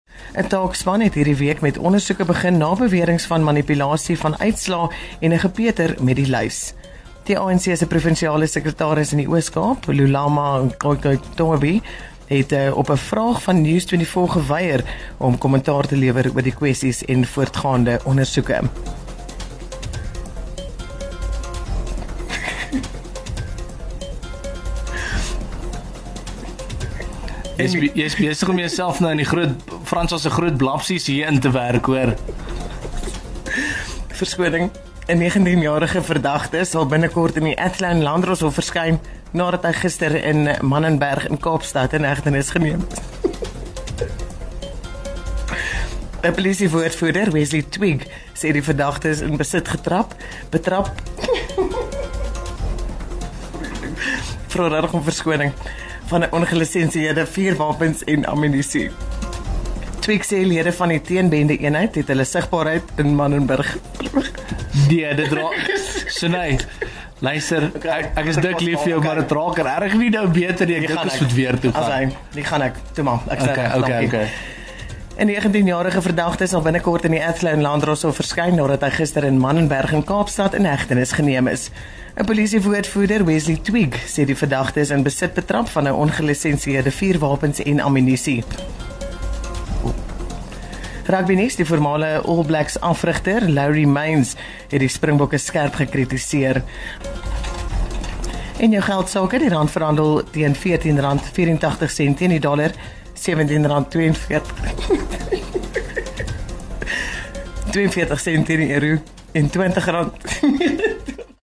kan nie ophou giggel terwyl sy nuus lees nie.